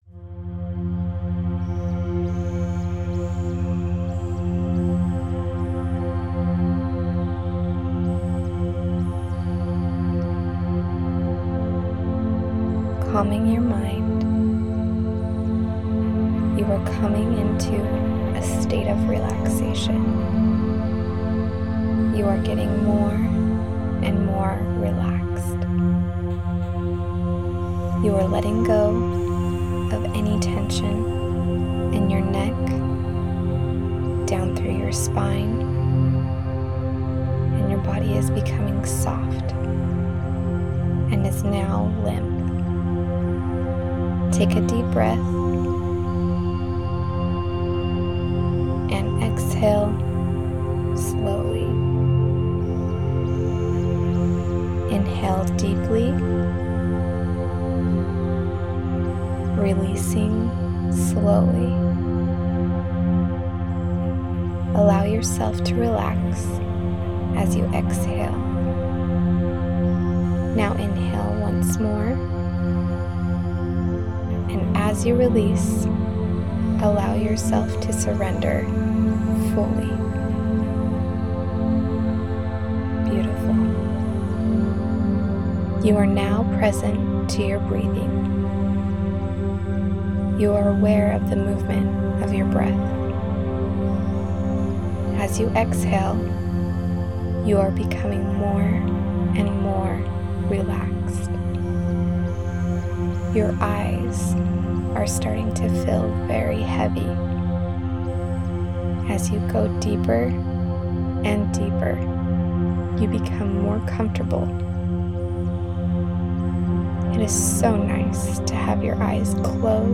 A guided meditation to help you reprogram your mindset in manifesting the things you desire!